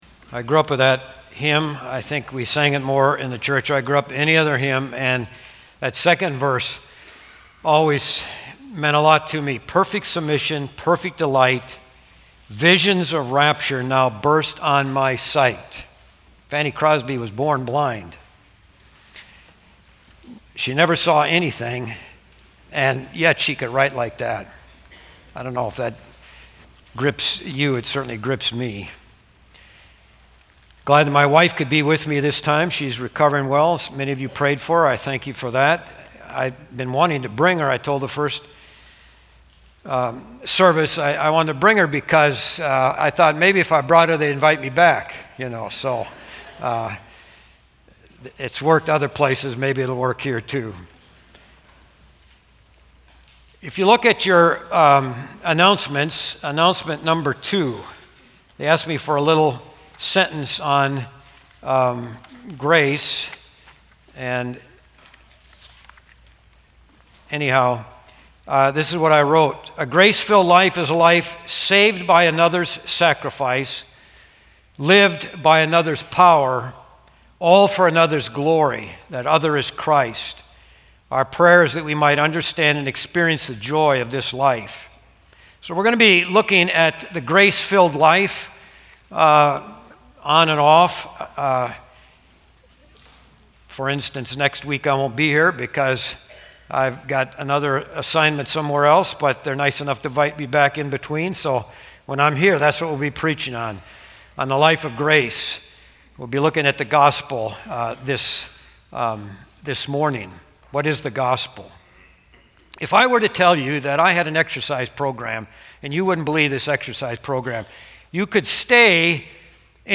A message from the series "Sunday Sermons." 2 Corinthians 9:6-1 a.m.